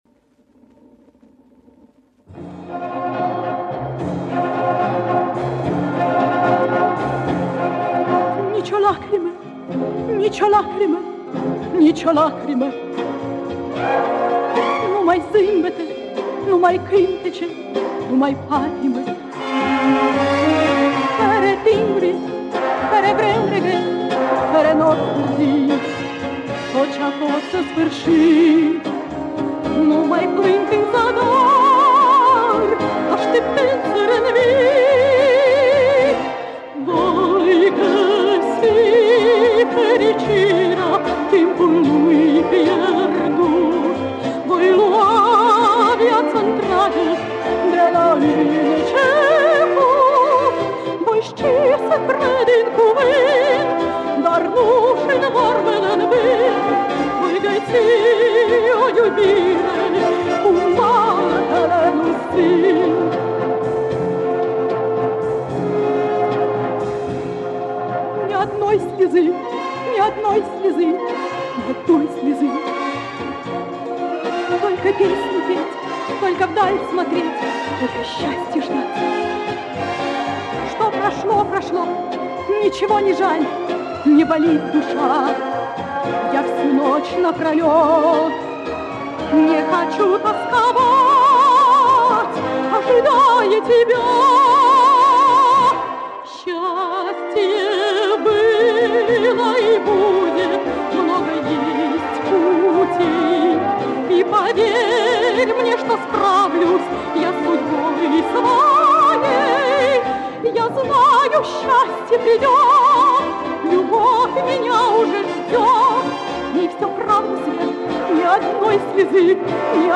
Оркестр